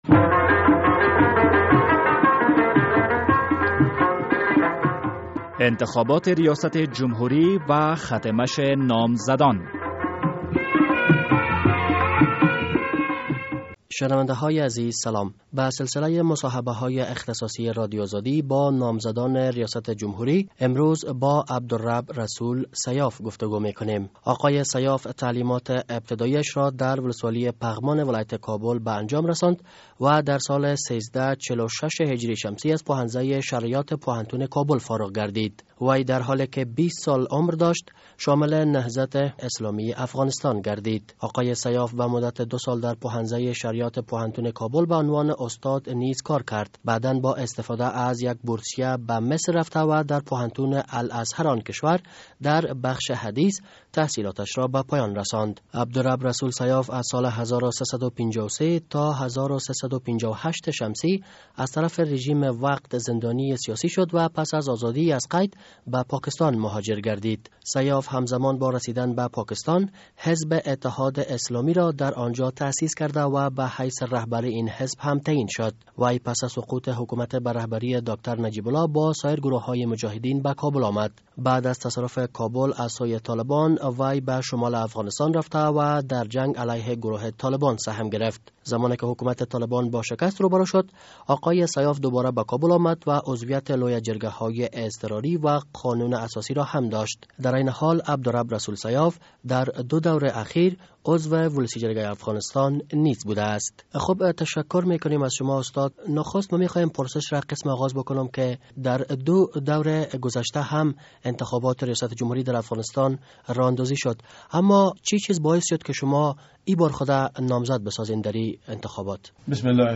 به سلسلهء مصاحبه های اختصاصی رادیو آزادی با نامزدان ریاست جمهوری امروز با عبدرب الرسول سیاف گفتگو شده است...